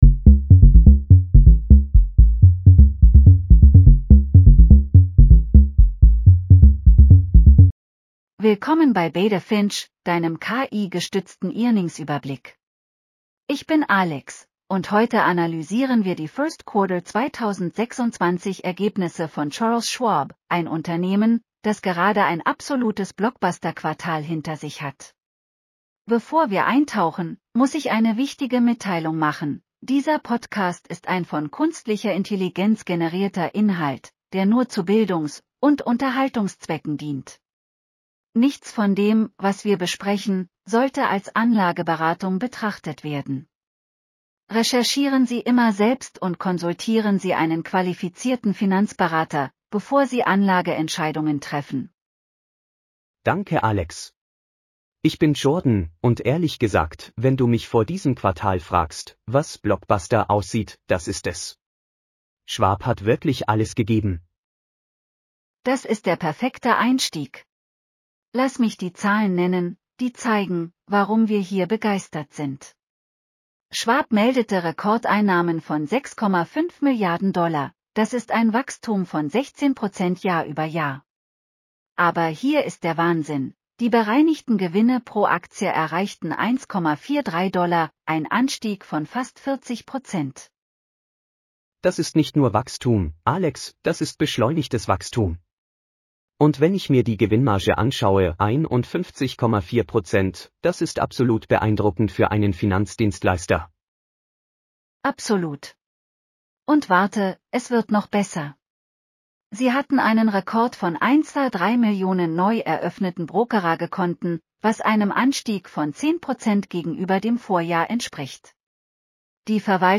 Charles Schwab Q1 2026 earnings call breakdown.